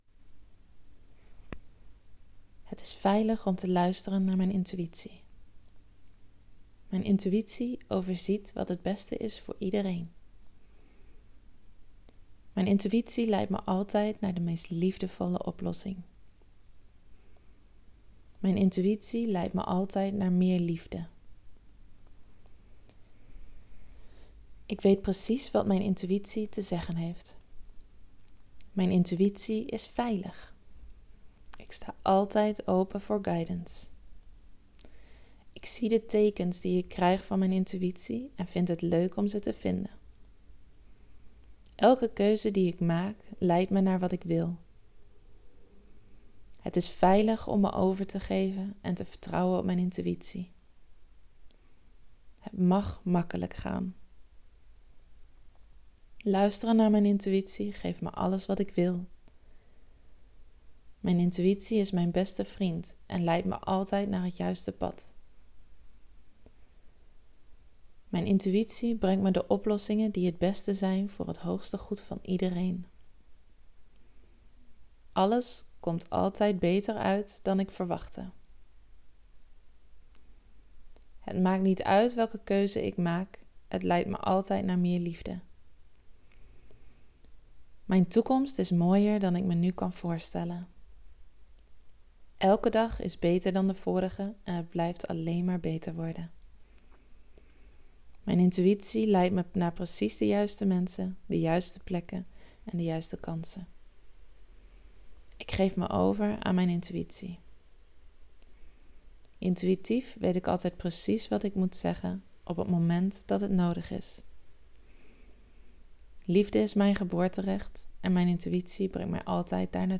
Dezelfde affirmaties in audiovorm, zodat je ze kunt luisteren wanneer je wil.